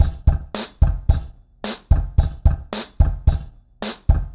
RemixedDrums_110BPM_20.wav